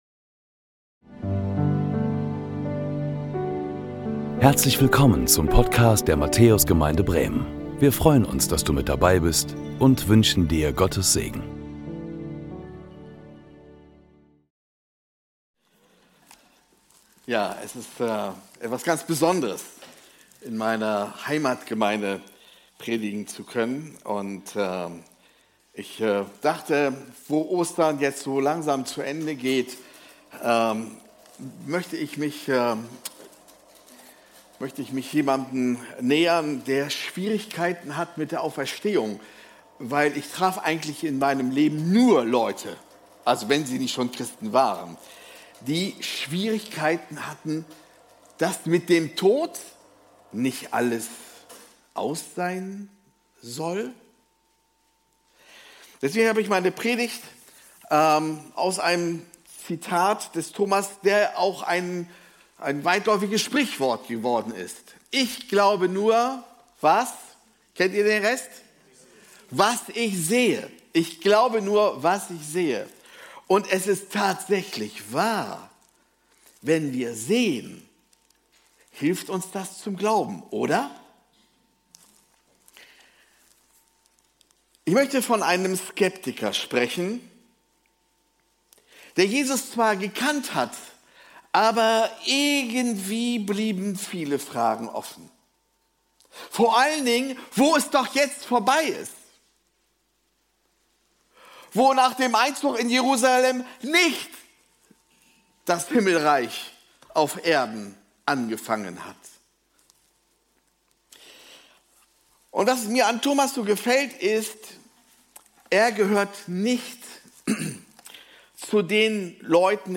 Willkommen zu unserem Gottesdienst aus der Matthäus Gemeinde Bremen!